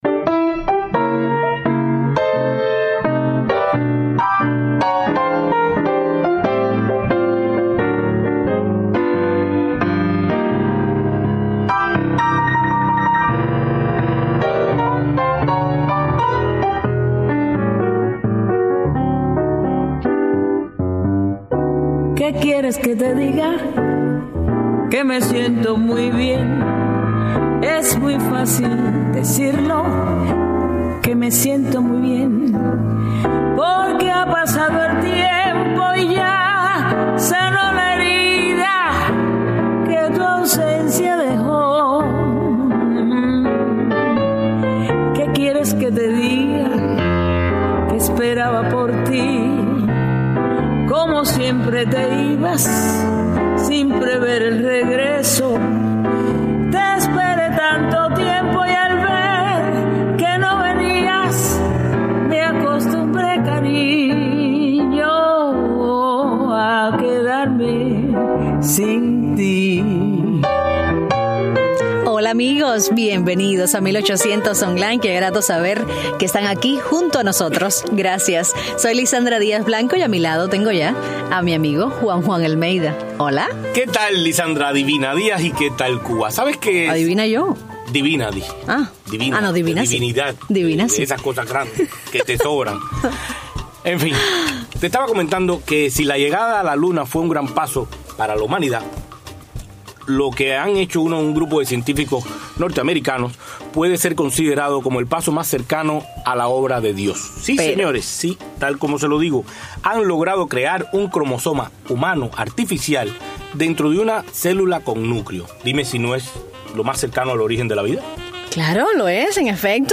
En vivo